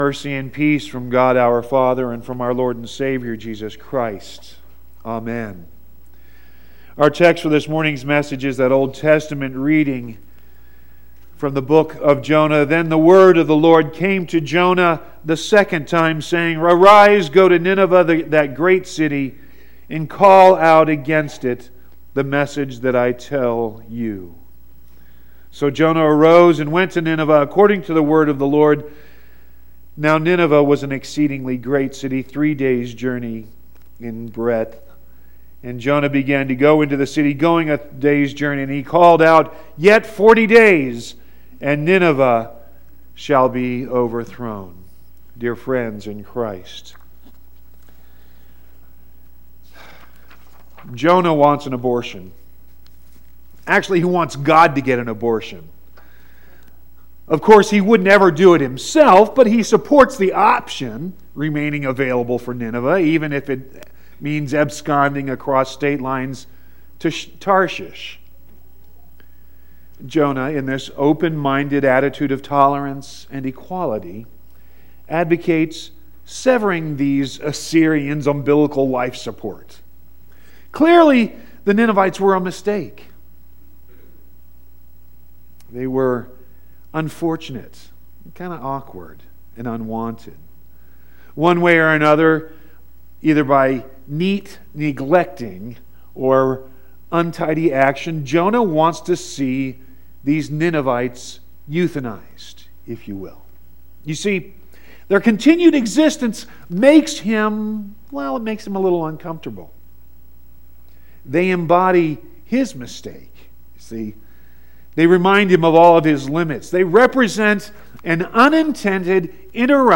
1-21-18-sermon.mp3